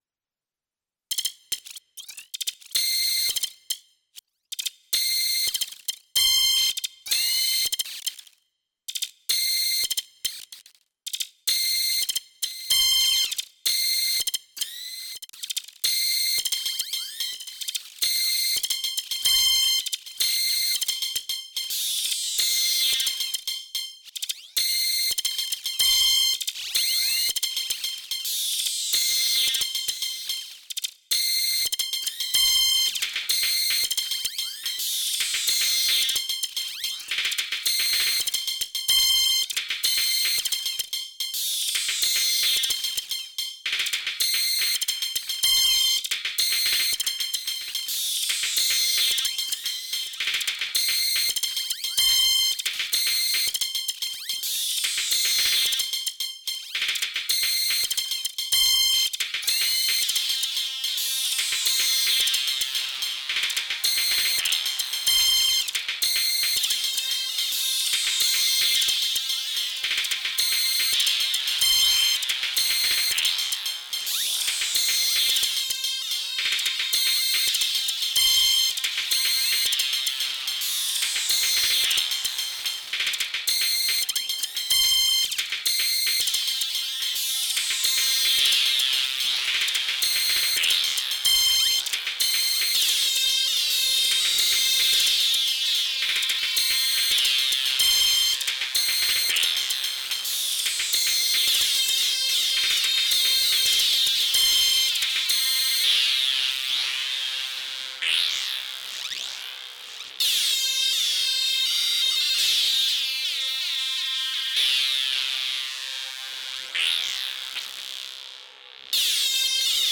Was really a challenge to build something worth sharing, and it’s severely lacking low end.